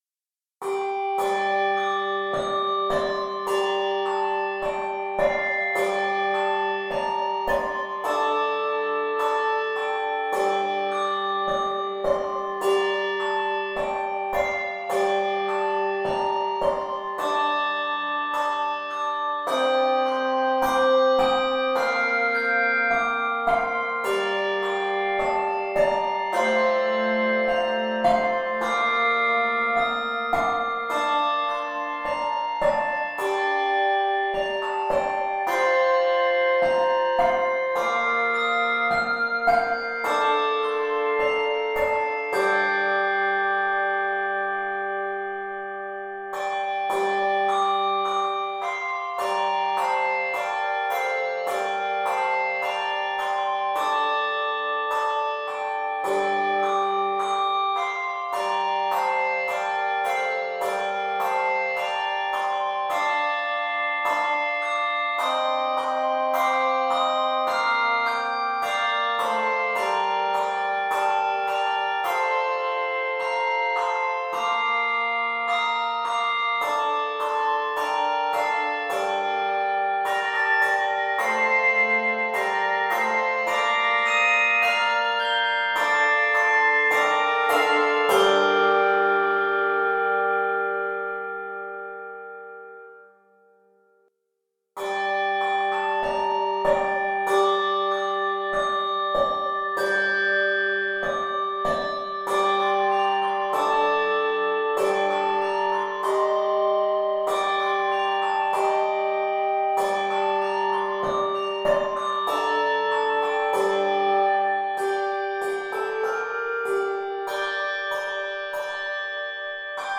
Both pieces are in the key of G Minor.